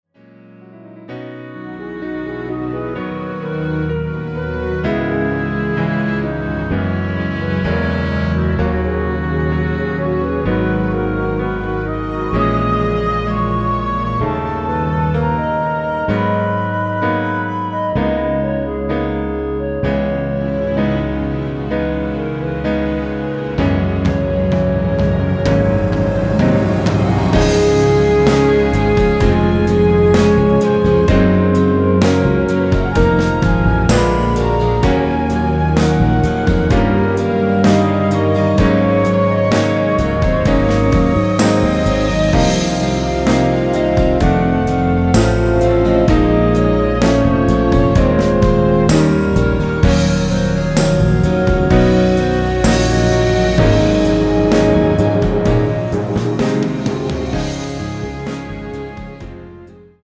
원곡이 너무 길어 축가나 행사에 사용하실수 있게 3분 53초로 편곡 하였습니다.
(-2)내린 멜로디 포함된 MR 입니다.(미리듣기 참조)
앞부분30초, 뒷부분30초씩 편집해서 올려 드리고 있습니다.
(멜로디 MR)은 가이드 멜로디가 포함된 MR 입니다.